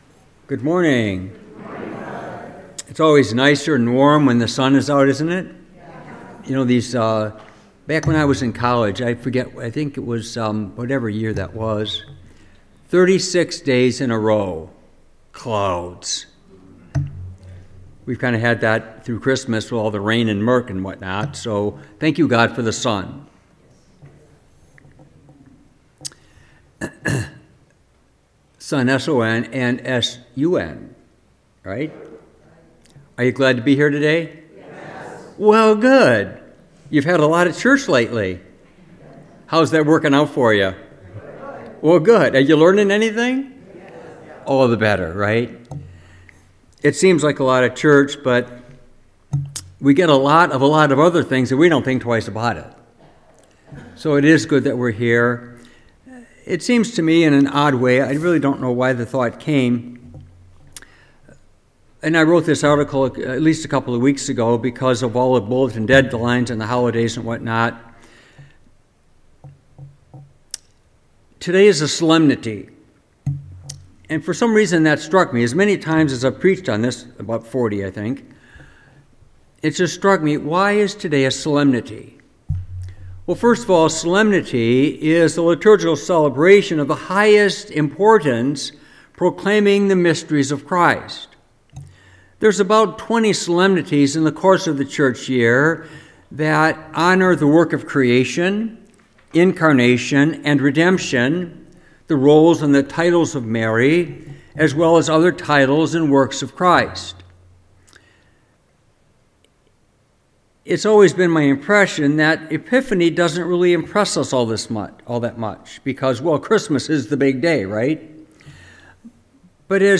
Homily, Epiphany 2025
Homily-Epiphany-24.mp3